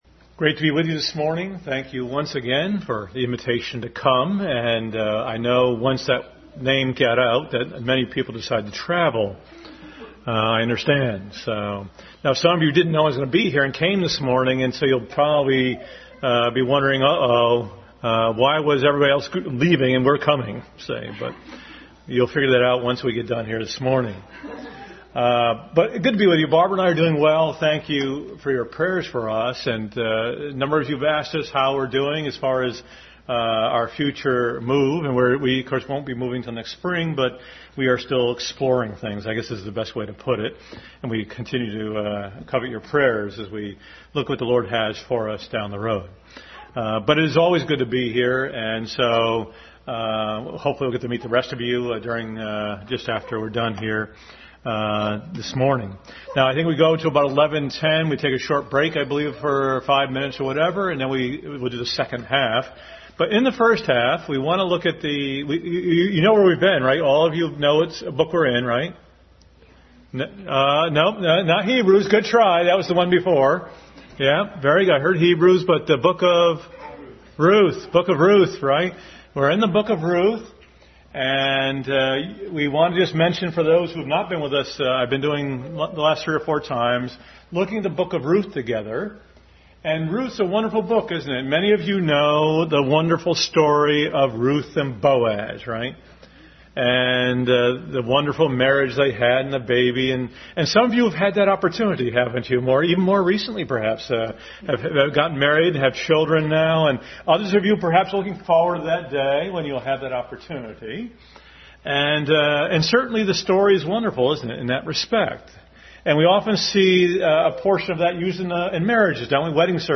Adult Sunday School Lesson.